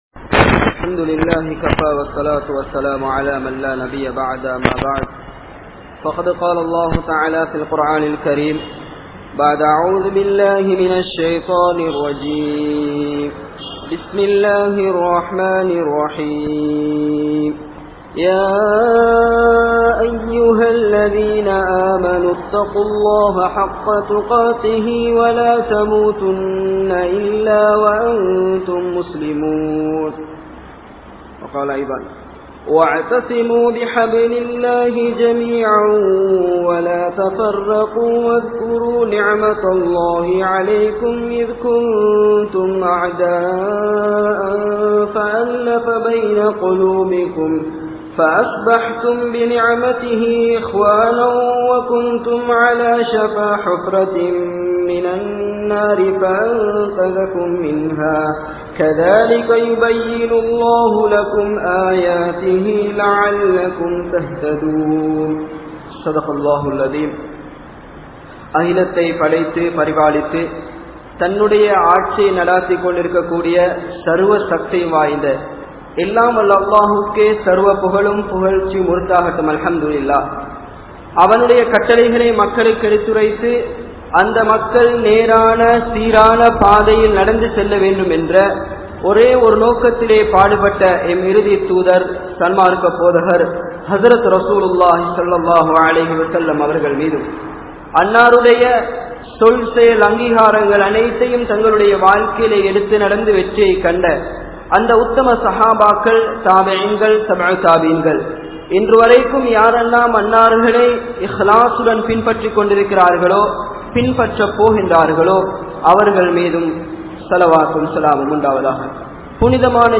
Ottrumai(unity) | Audio Bayans | All Ceylon Muslim Youth Community | Addalaichenai